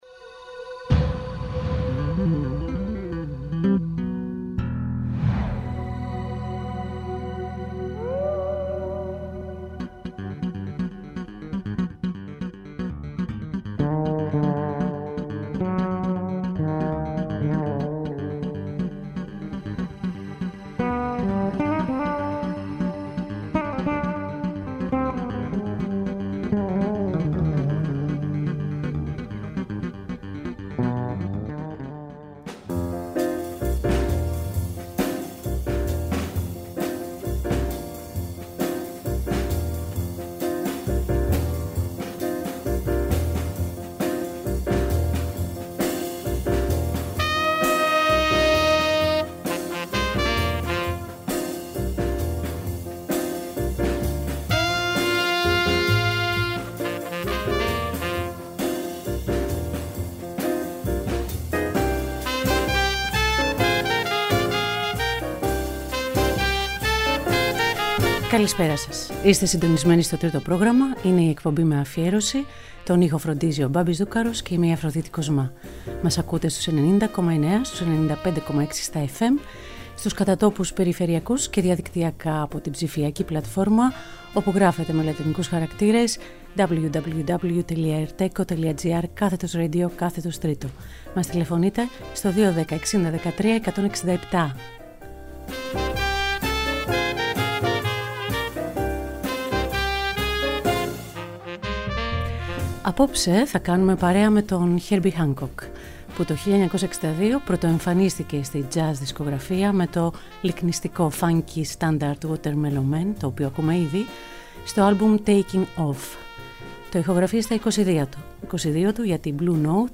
Ζωντανά από το στούντιο του Τρίτου Προγράμματος.